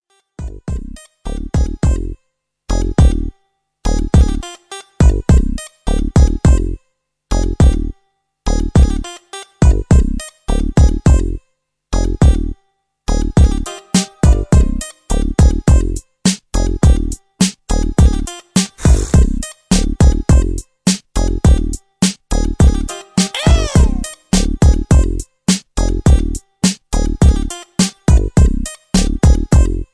backing tracks
rap, r and b, hip hop